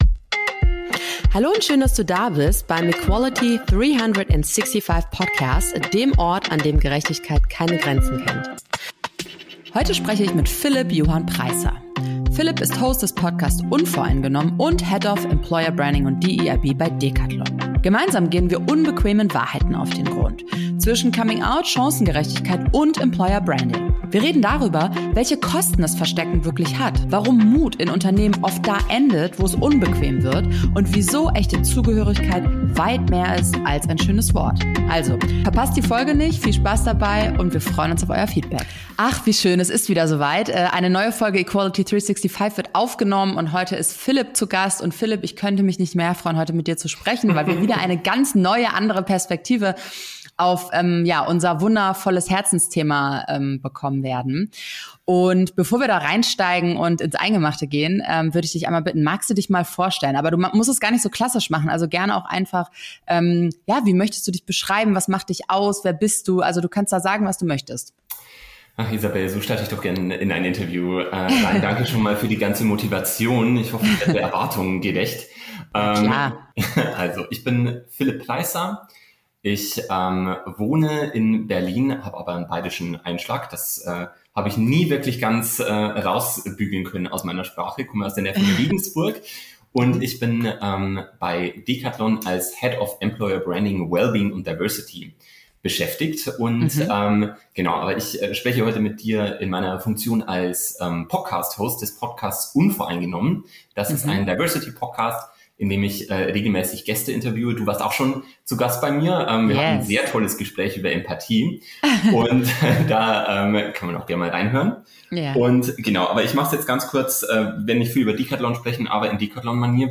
Ein Gespräch, das hängen bleibt.